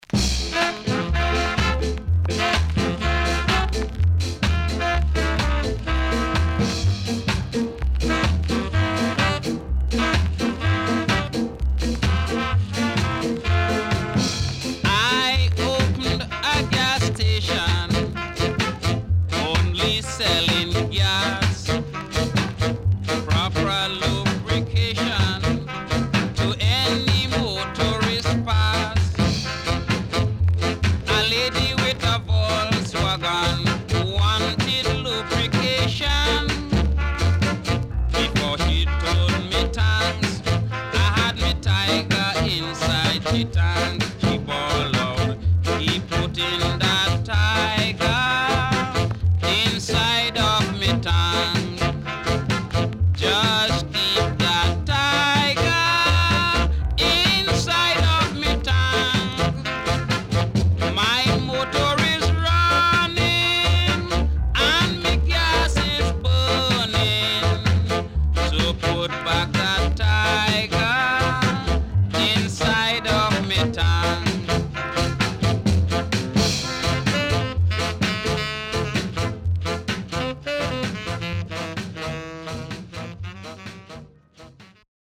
HOME > Back Order [VINTAGE 7inch]
SIDE A:所々チリノイズ、プチノイズ入ります。